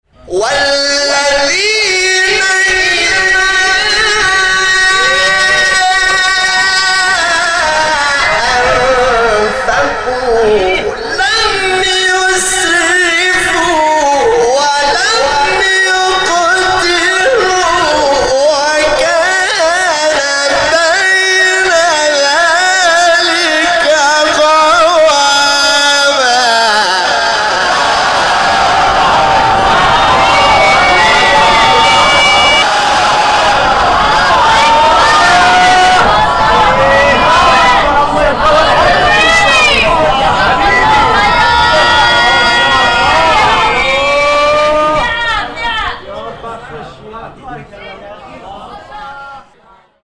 سوره : فرقان آیه : 67 استاد : حامد شاکرنژاد مقام : بیات قبلی بعدی